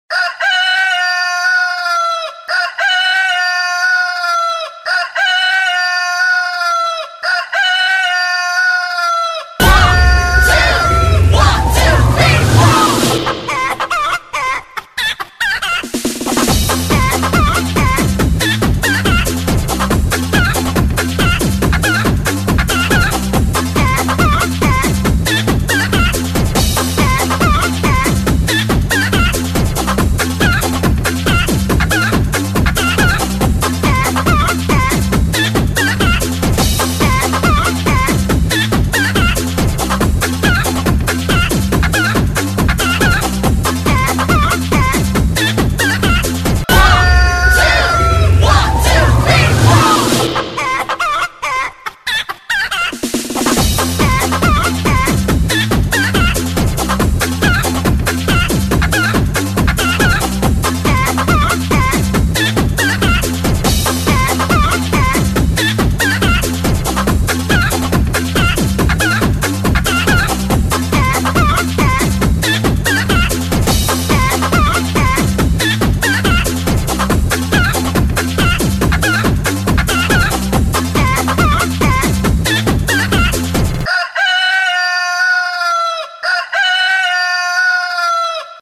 А для друзей , делюсь пением кошек и петушка - от них  волей-неволей поднимкшься.